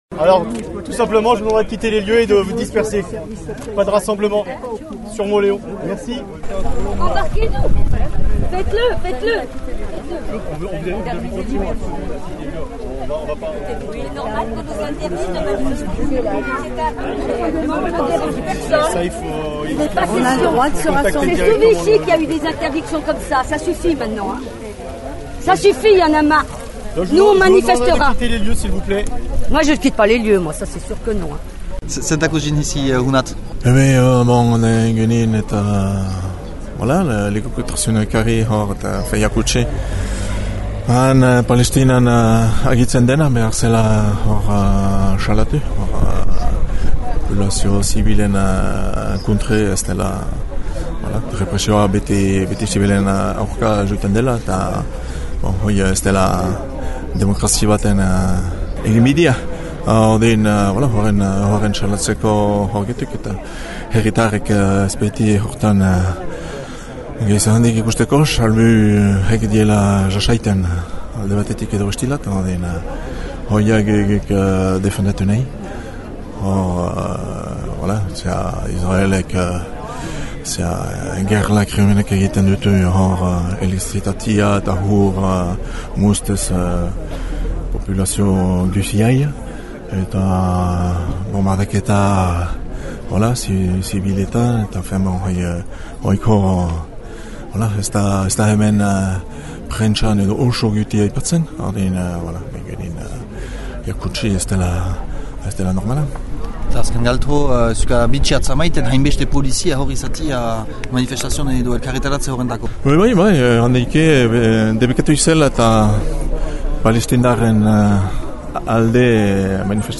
Bildütako lekükotarzünak :